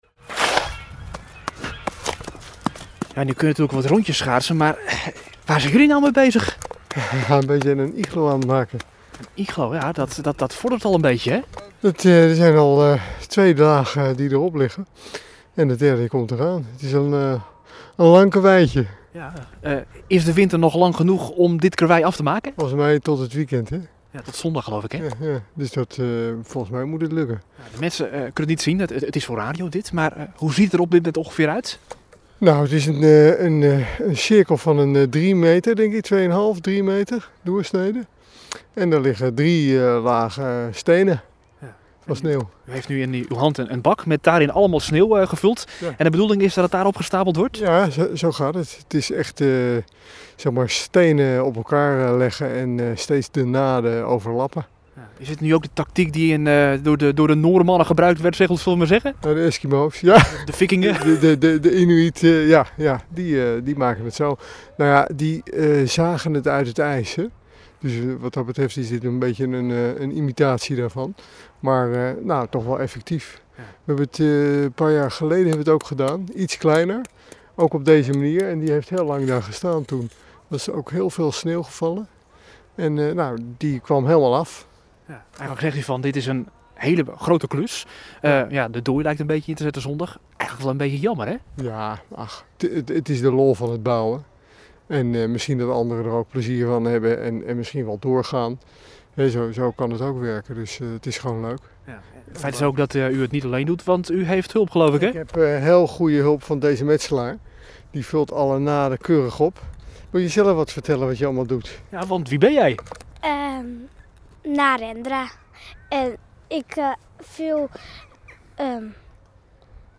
in gesprek met de bouwers